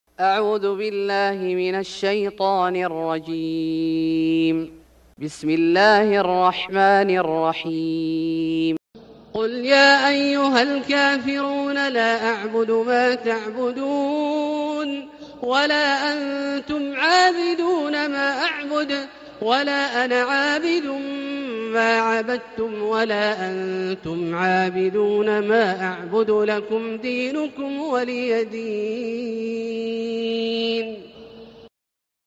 سورة الكافرون Surat Al-Kafirun > مصحف الشيخ عبدالله الجهني من الحرم المكي > المصحف - تلاوات الحرمين